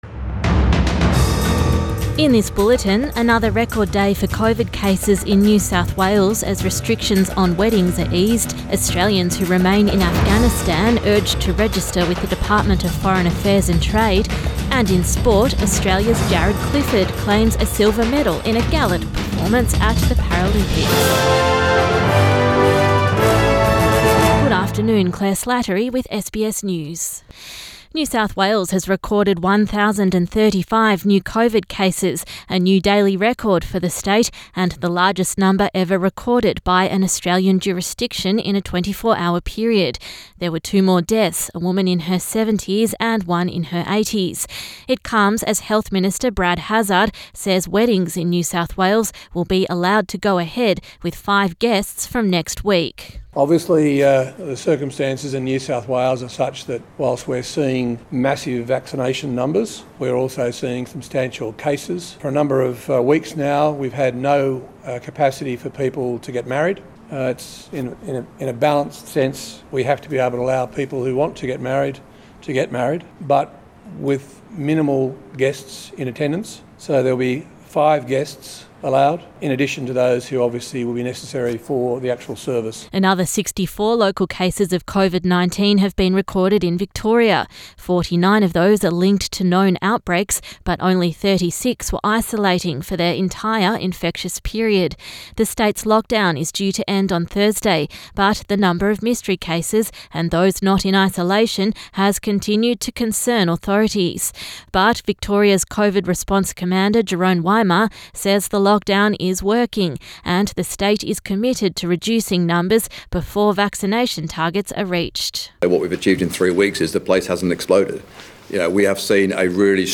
PM bulletin 28 August 2021